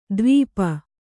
♪ dvīpa